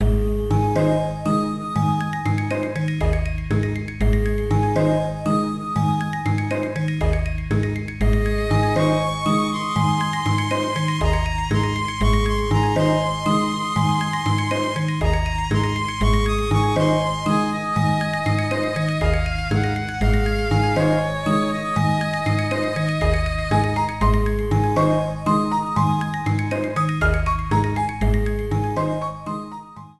Clipped to 30 seconds and added fade-out.